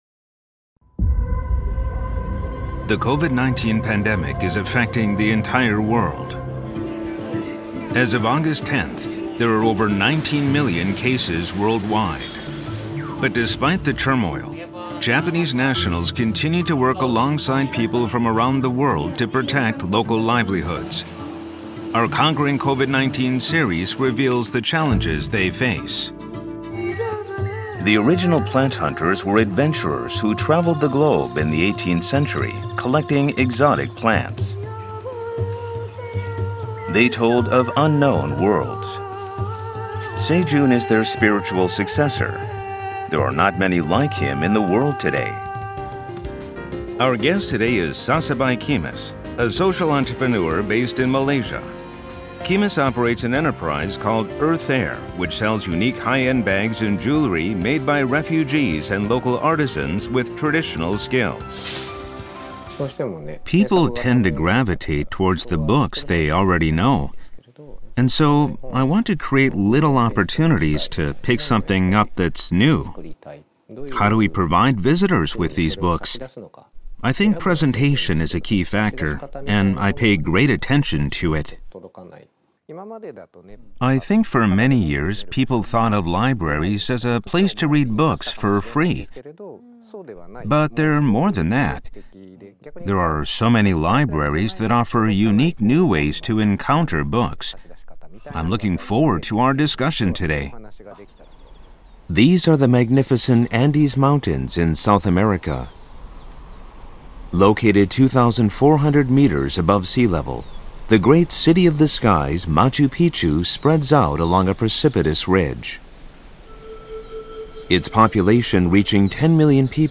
英語ナレーターキャスティング
信頼感を感じさせる落ち着いた喋りが、今回の作品にもフィットしていました。